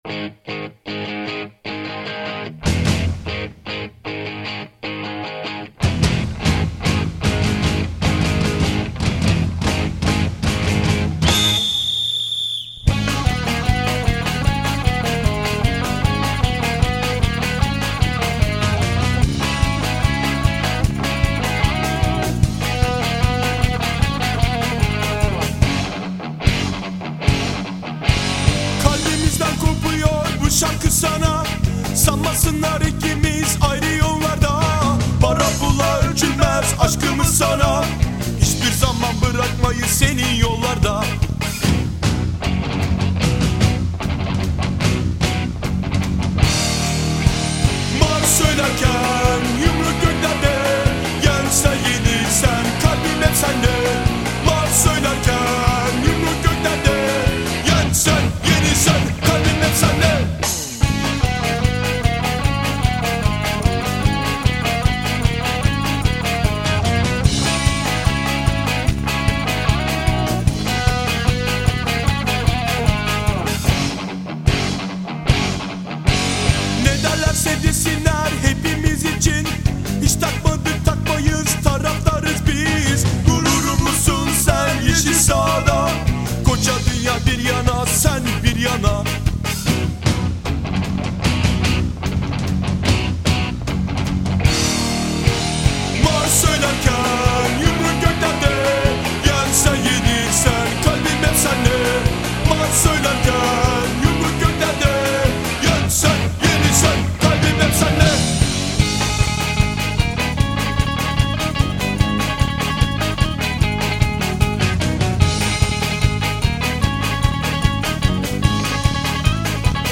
популярная   турецкая панк-рок  группа.